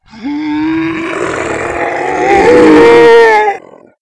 zombie_alert2.wav